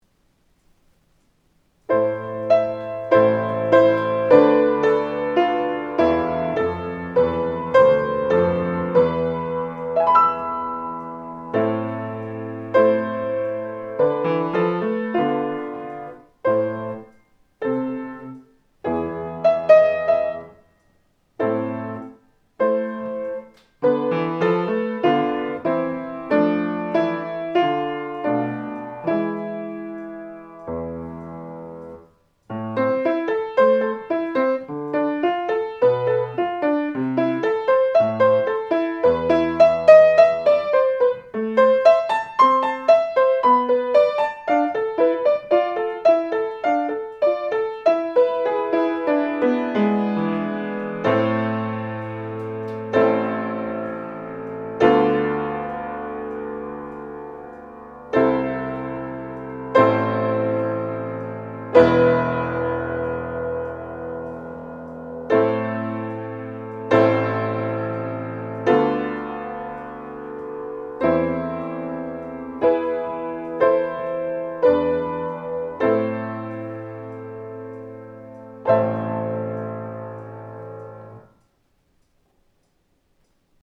⭐予選審査では、下記の課題曲の伴奏をご利用いただくことも可能です。
十五夜お月さん前奏４小節